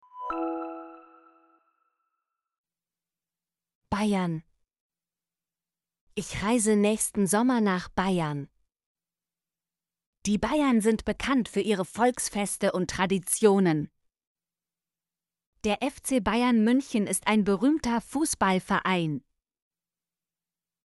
bayern - Example Sentences & Pronunciation, German Frequency List